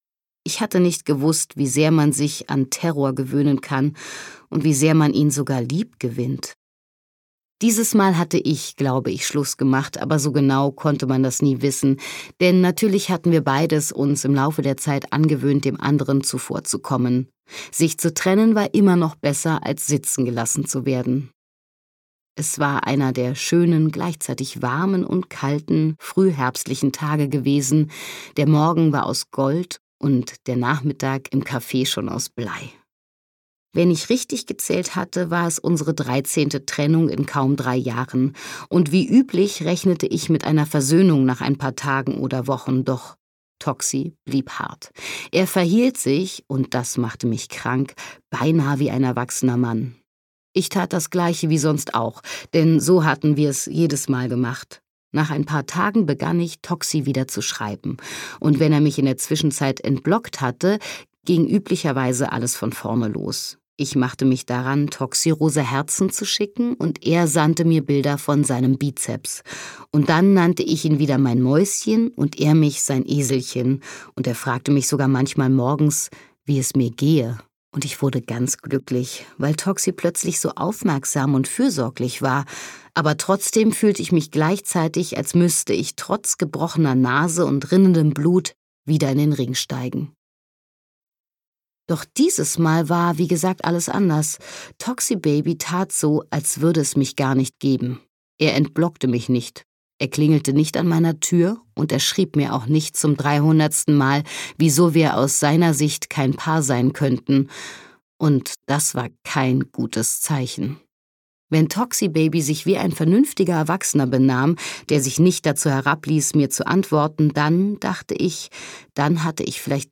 Toxibaby - Dana von Suffrin | argon hörbuch
Gekürzt Autorisierte, d.h. von Autor:innen und / oder Verlagen freigegebene, bearbeitete Fassung.